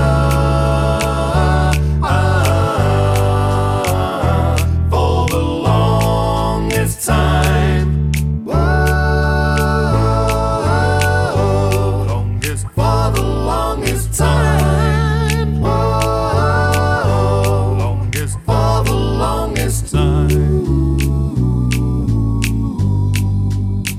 One Semitone Down Pop (1980s) 3:38 Buy £1.50